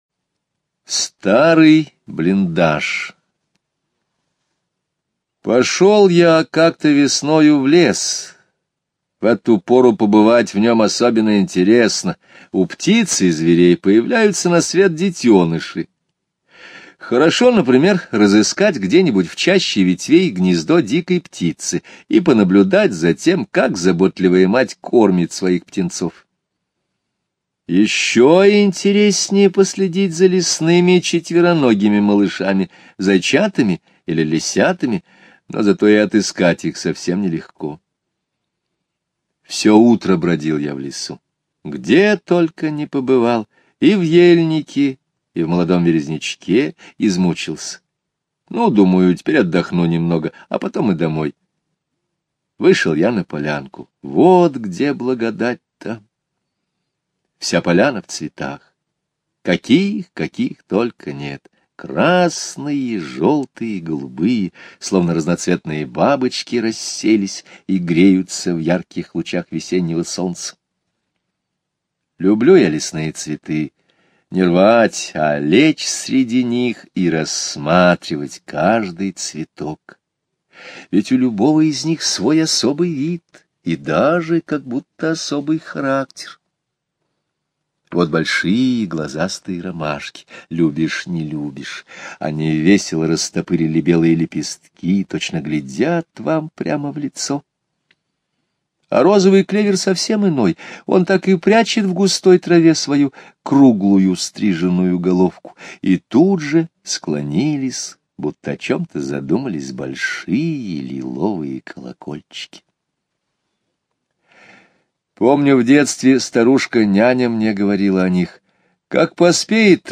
Слушайте Старый блиндаж - аудио рассказ Скребицкого Г. История о том, как автор долго гулял по лесу и набрел на старый блиндаж.